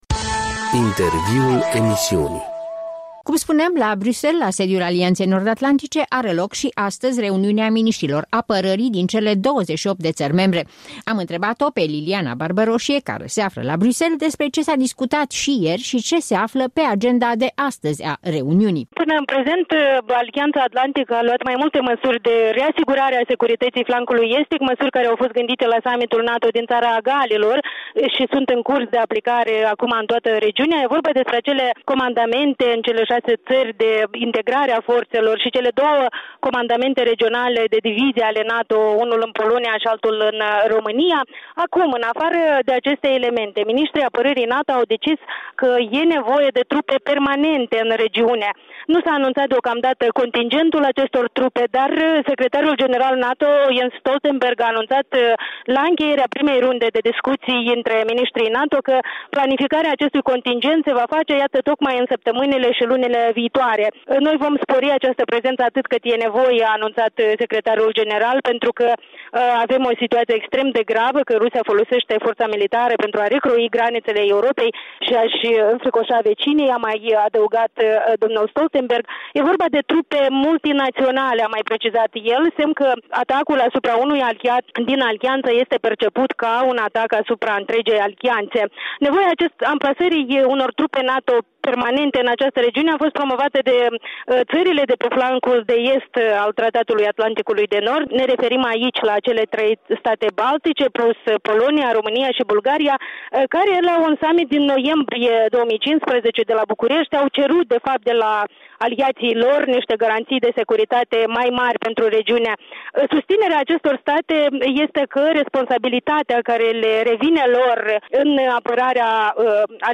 În direct de la sediul NATO la Bruxelles